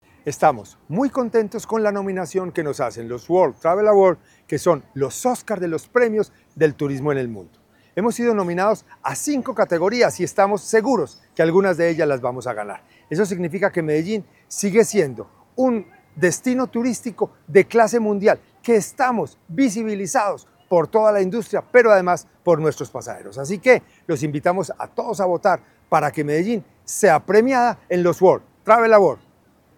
Audio Palabras de José Alejandro González Jaramillo, secretario de Turismo y Entretenimiento Medellín vuelve a ser protagonista en el escenario internacional del turismo con cinco nominaciones en los World Travel Awards 2025, conocidos como los “Oscar del turismo”.